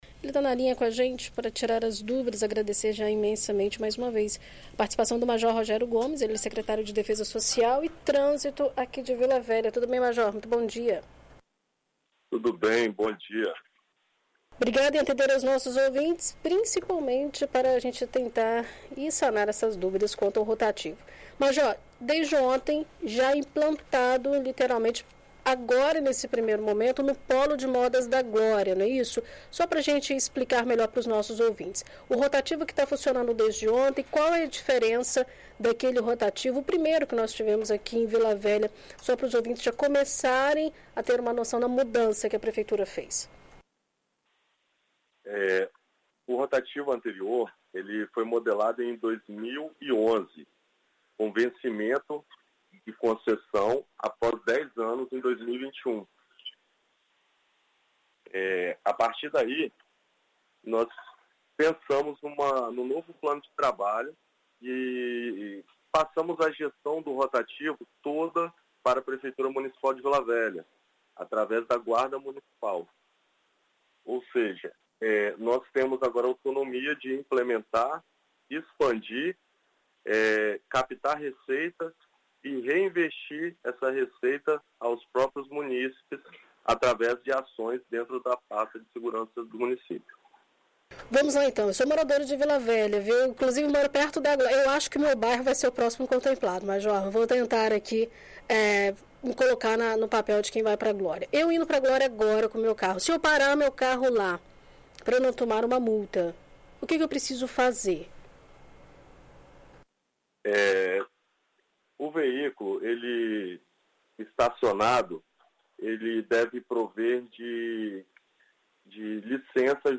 Em entrevista a BandNews FM Espírito Santo nesta terça-feira (24), o Secretário de Defesa Social e Trânsito de Vila Velha, Major Rogério Gomes, esclarece as dúvidas sobre o uso dos rotativos de Vila Velha.